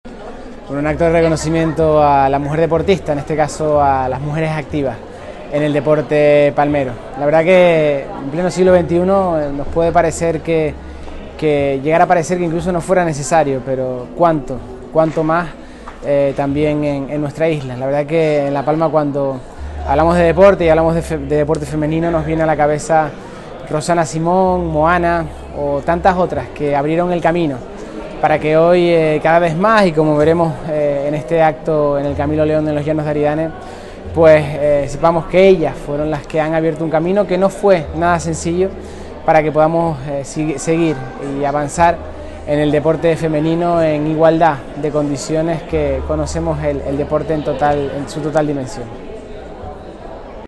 Declaraciones Mariano Zapata audio mujeres deportistas.mp3